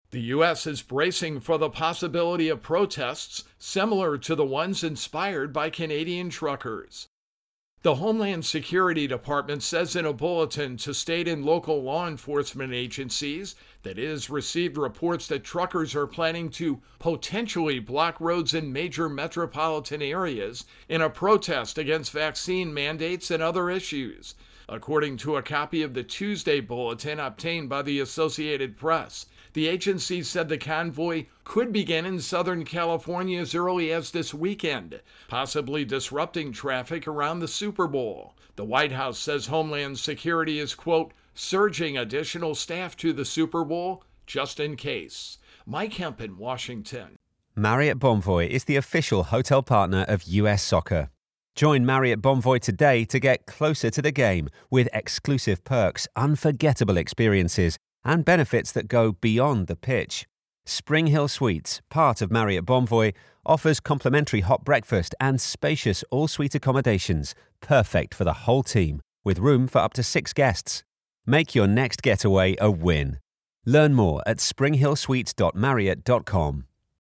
Intro and voicer for Virus Outbreak-Canada Protests.